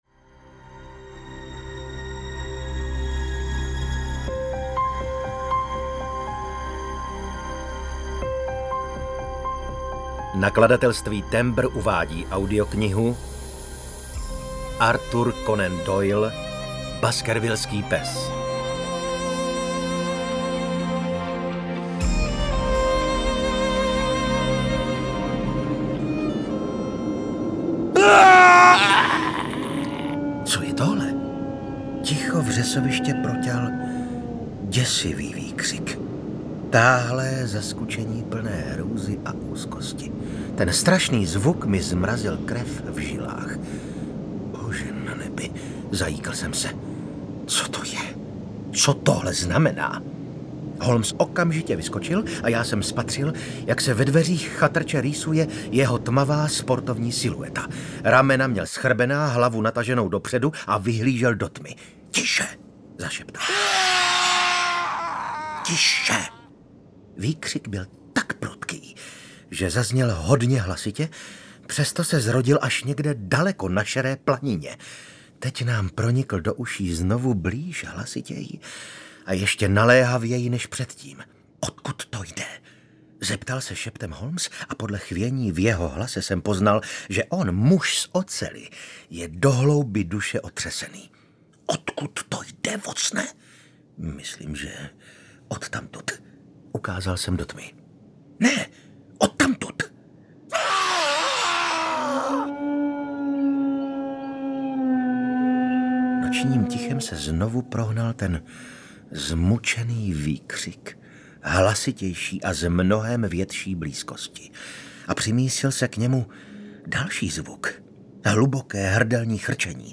UKÁZKA Z KNIHY
Čte: Vasil Fridrich
audiokniha_baskervillsky_pes_ukazka.mp3